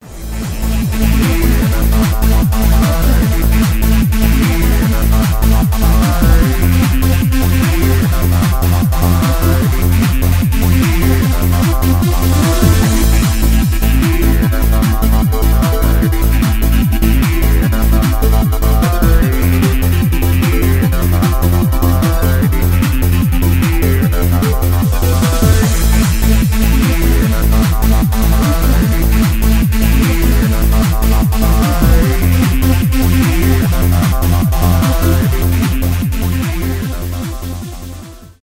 электронные , техно